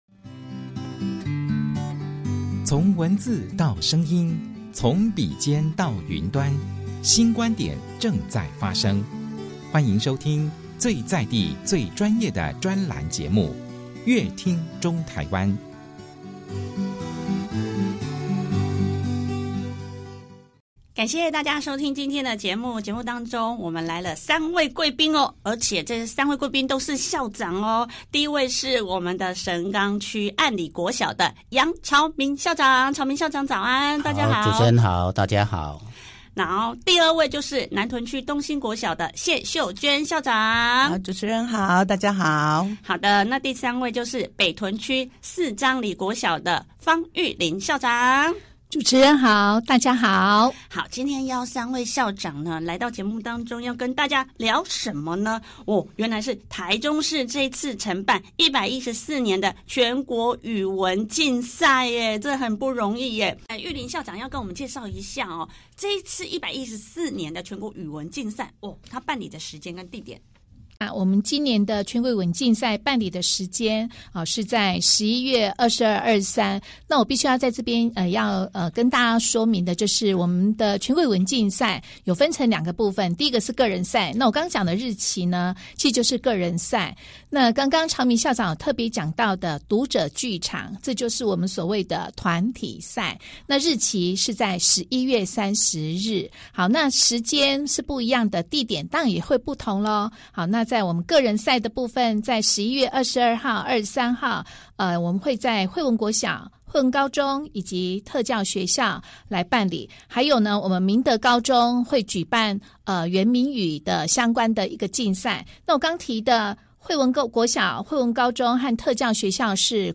想知道更多精彩的訪談內容，請鎖定本集節目。